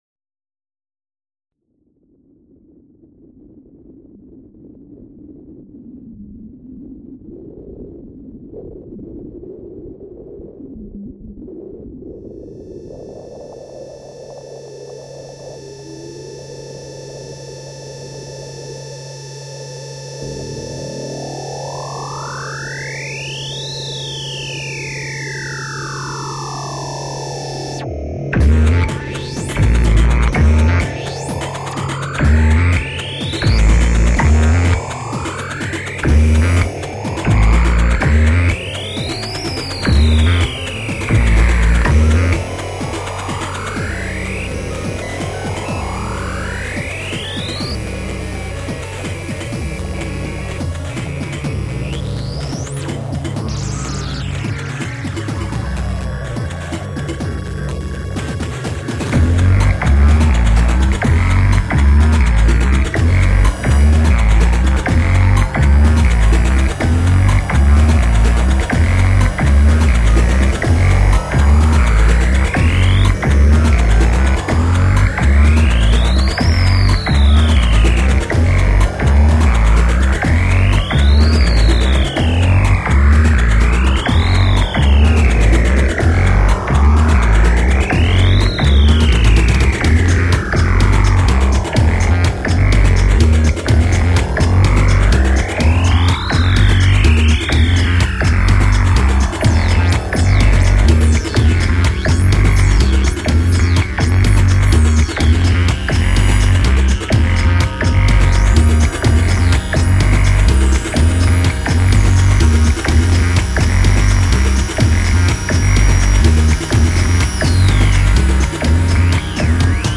would fit in a Rave Party...
Recorded in Felina (Reggio Emilia, Italy) in august 2003